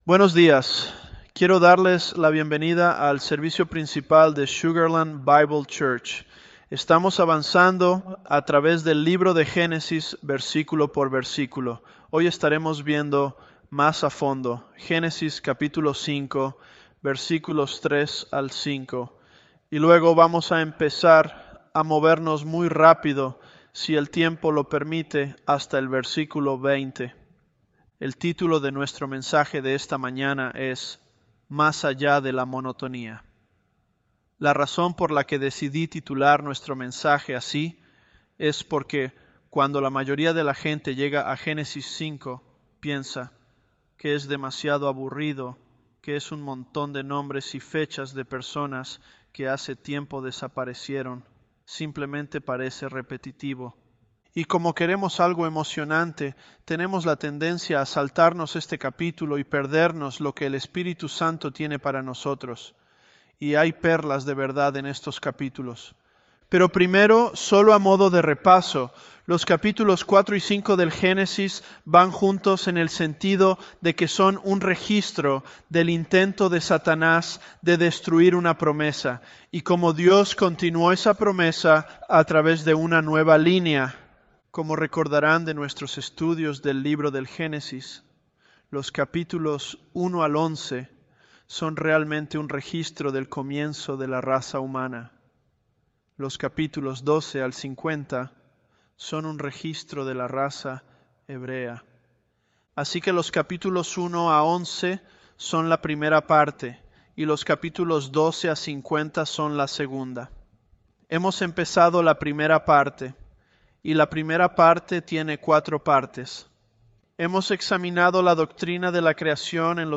Sermons
ElevenLabs_Genesis-Spanish021c.mp3